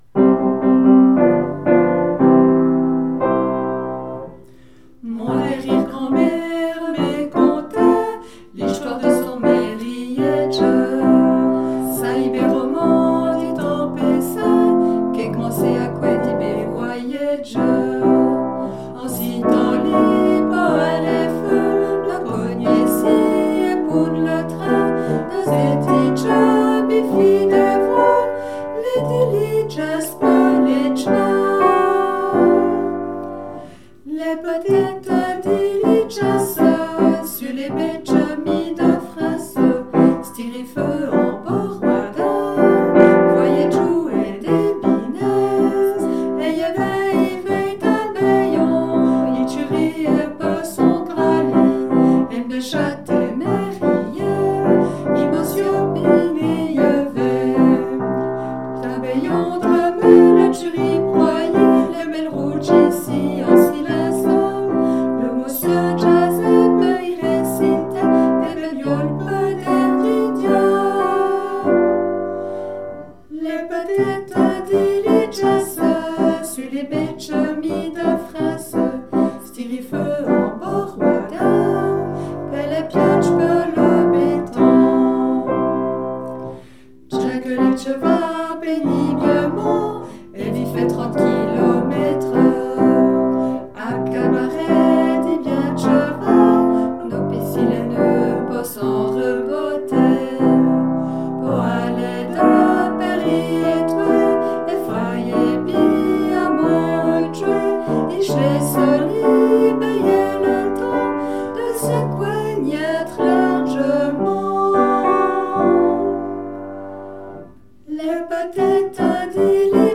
Patois Jurassien
chant et piano 110430